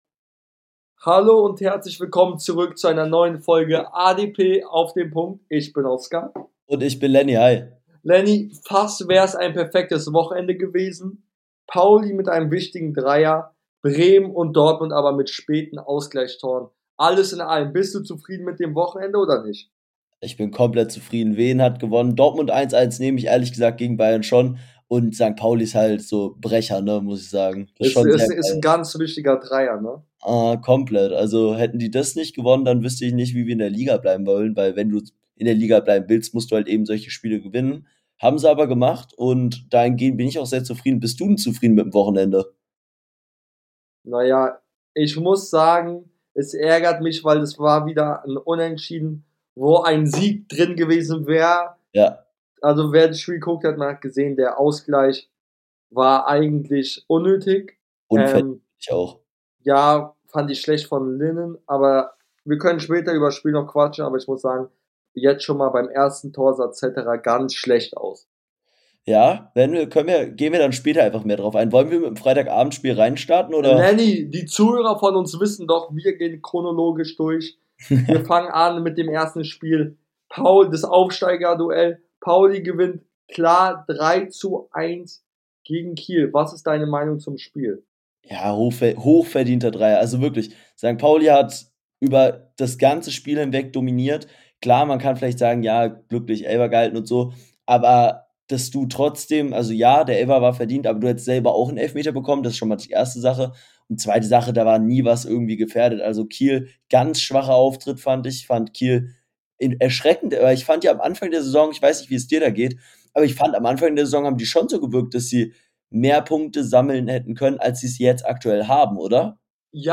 In der heutigen Folge reden die beiden Hosts über den Downfall von Leipzig und City und die Gründe dafür , Paulis wichtiger Sieg und vieles mehr